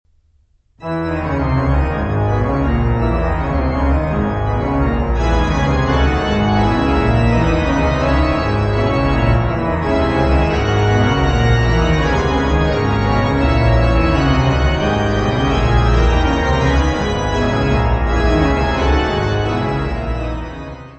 Music Category/Genre:  Classical Music
VII Allegro (organ solo).